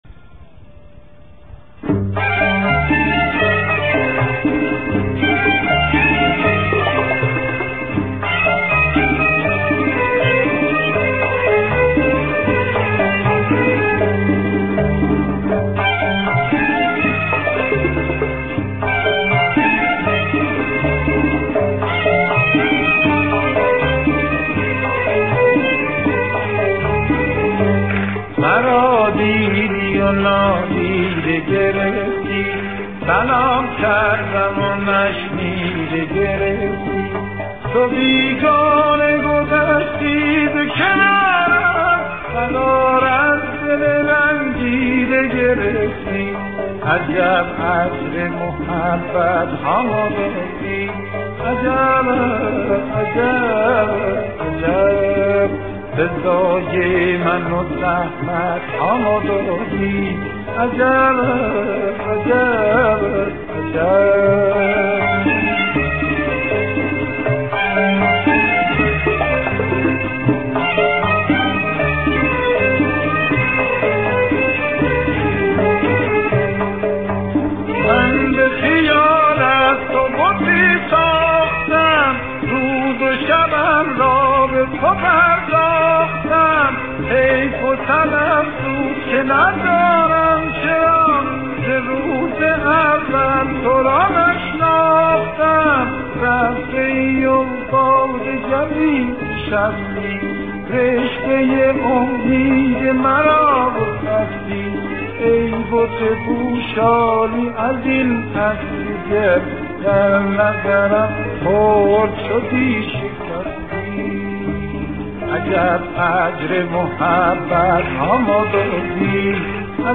خوانندهٔ موسیقی اصیل ایرانی است .